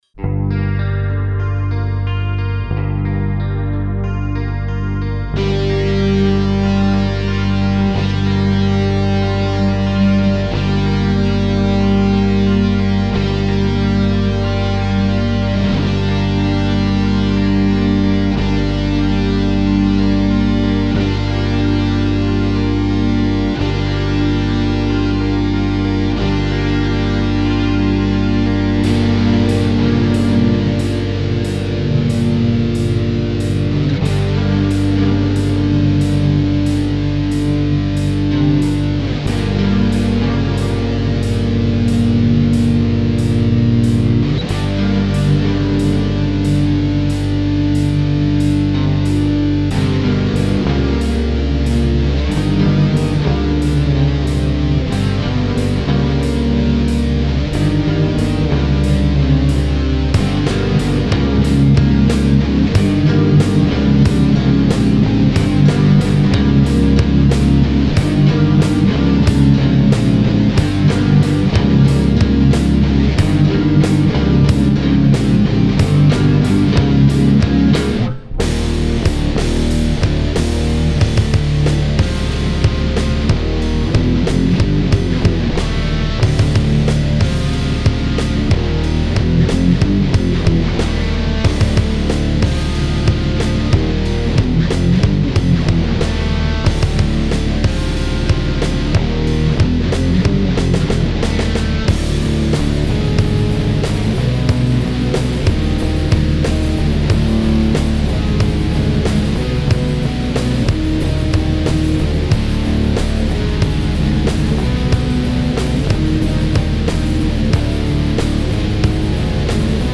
HEF (Instrumental Backing Track)